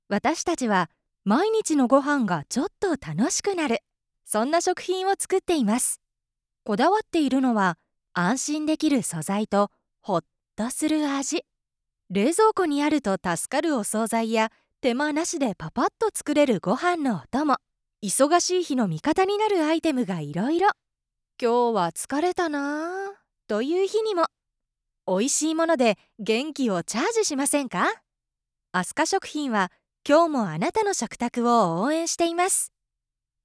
元CATVアナウンサーが温かみのある爽やかな声をお届けします。
落ち着いた、穏やかな